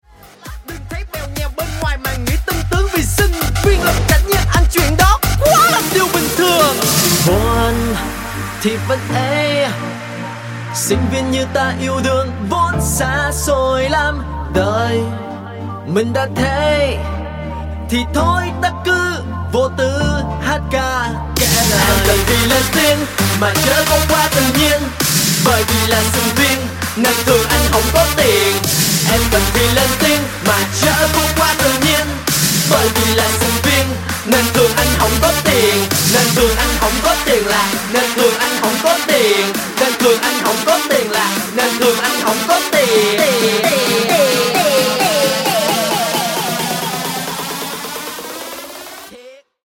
G House Version